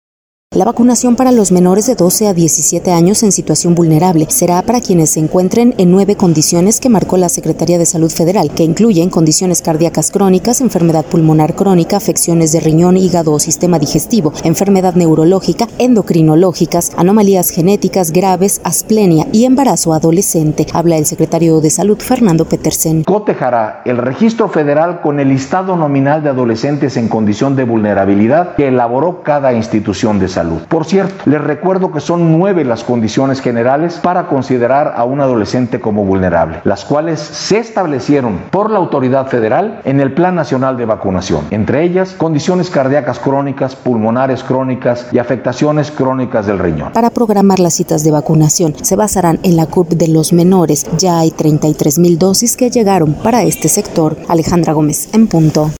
Habla el secretario de Salud Fernando Petersen: